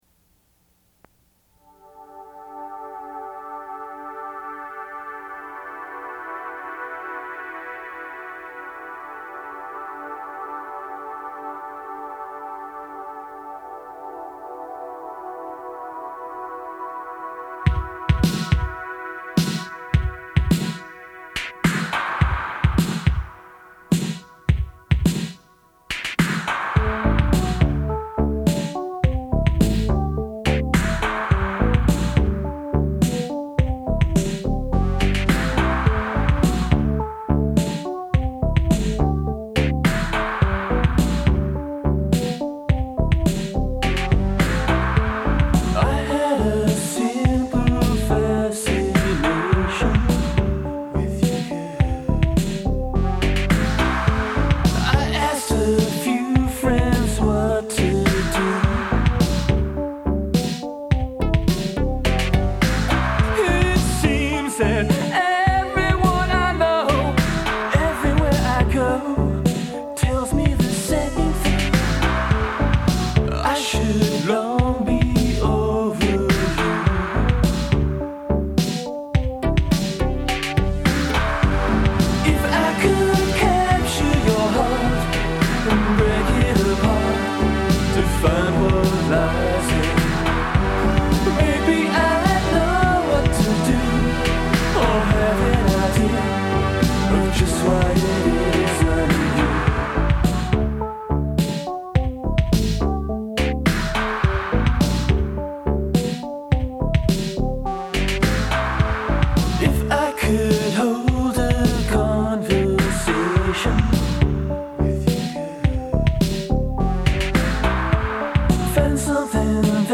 This work was done at a studio in downtown Cleveland
At the time, I was enamored with the work that Tears for Fears was doing on Songs from the Big Chair and I tried to bring some of that to the table here.